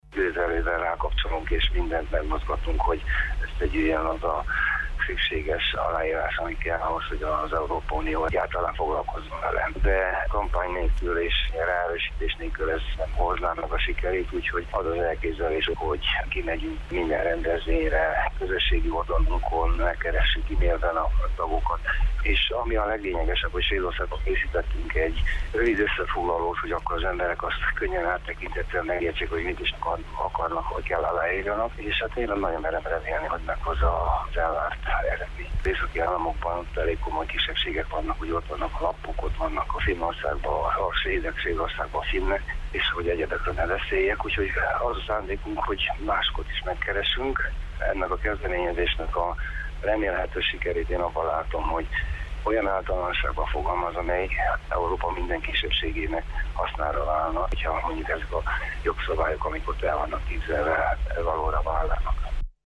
nyilatkozott rádiónknak.